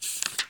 x_enchanting_scroll.7.ogg